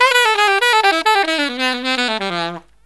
63SAXMD 06-L.wav